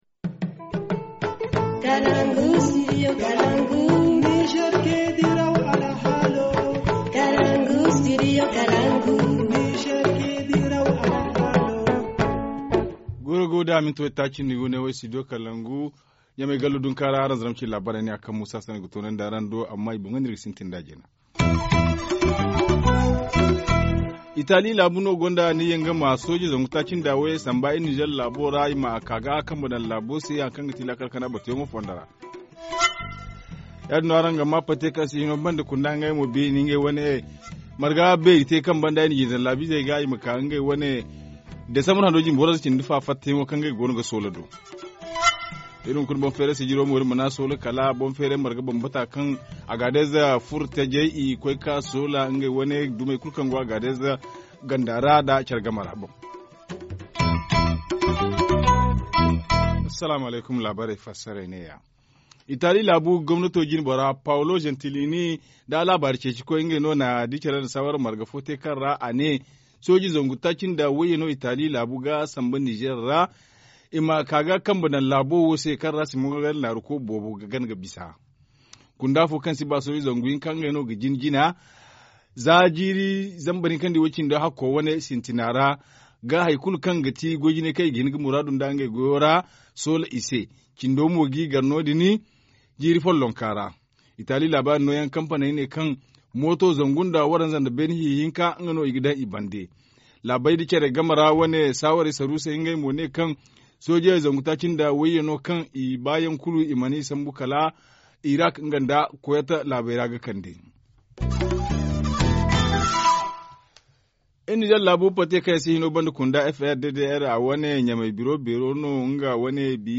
Journaux du 29 décembre 2017 - Studio Kalangou - Au rythme du Niger